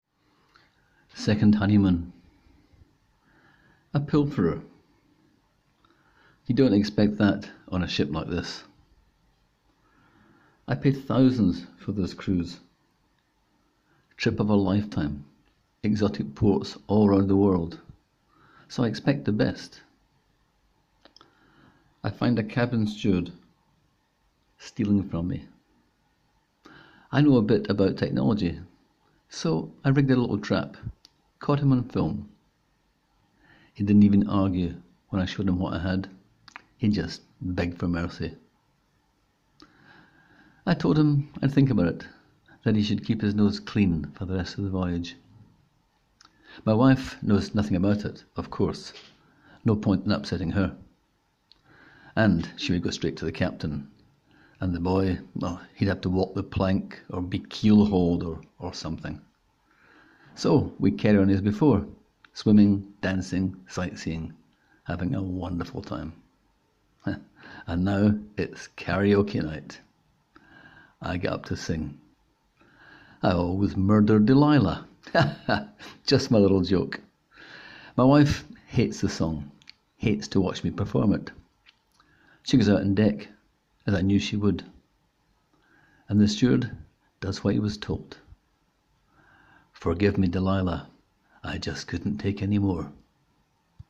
Click here to hear the author read the story: